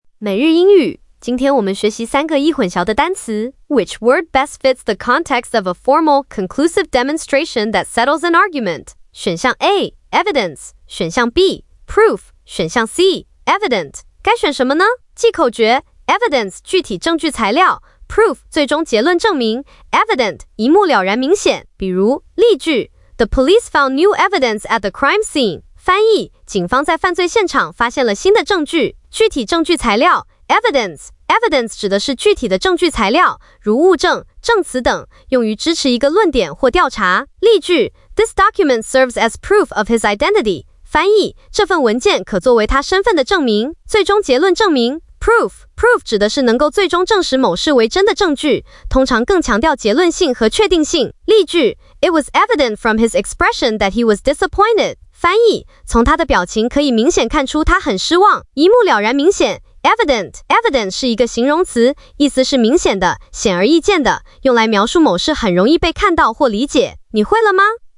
🎧 语音讲解